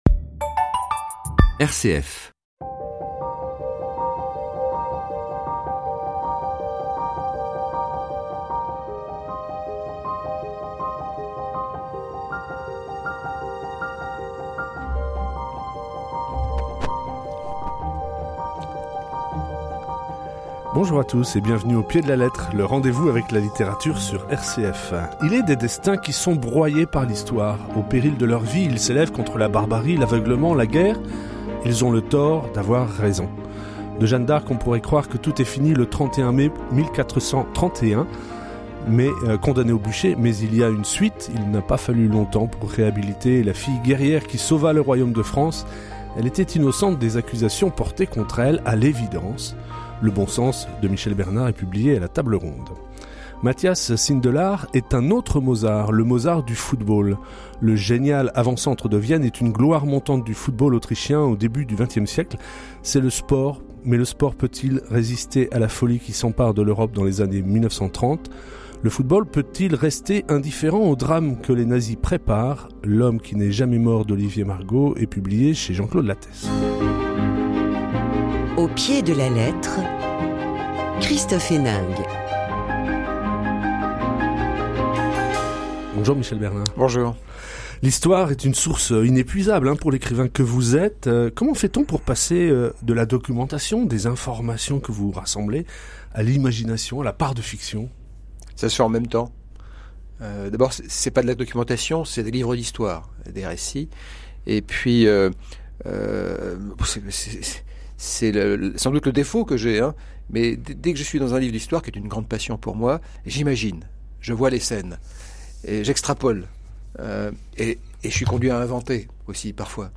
Écoutez cette chronique (à 50’40) :